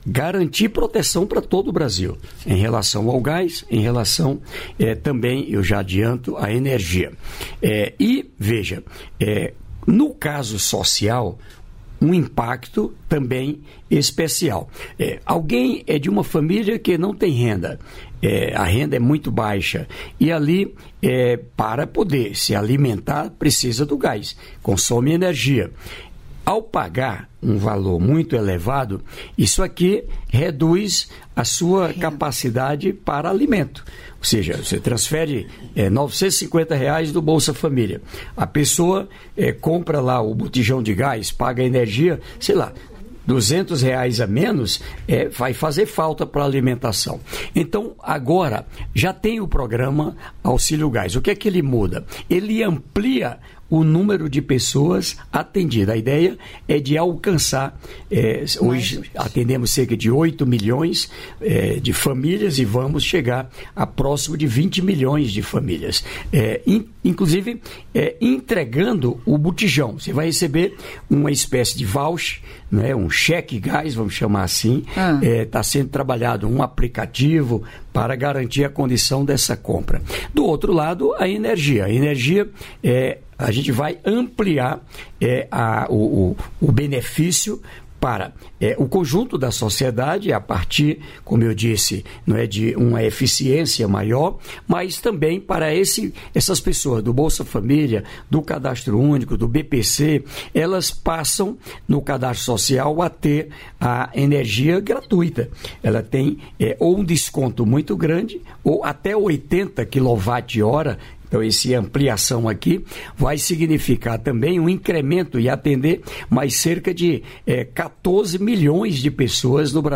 Trecho da participação do ministro do Desenvolvimento e Assistência Social, Família e Combate à Fome, Wellington Dias, no programa "Bom Dia, Ministro" desta quinta-feira (7), nos estúdios da EBC em Brasília (DF).